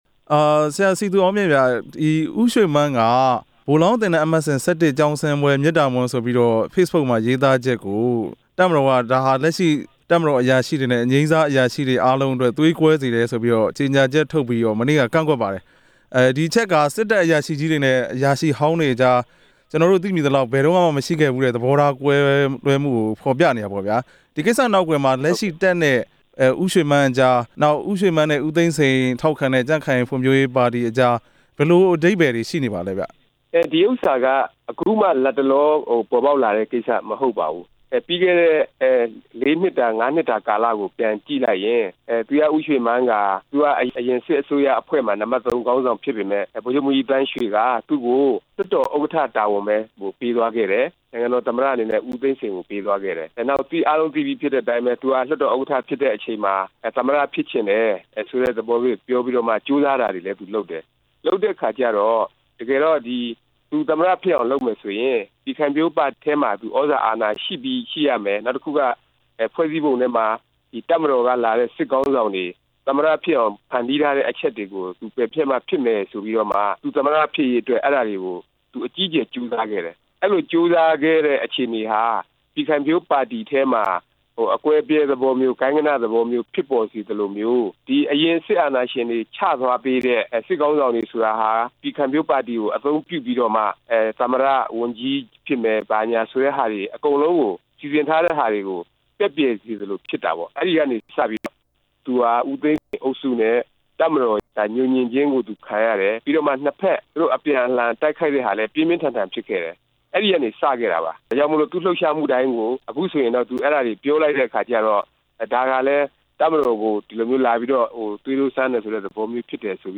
သူရဦးရွှေမန်း ရေးသားချက်ကို တပ်မတော် ကန့်ကွက်တဲ့အကြောင်း မေးမြန်းချက်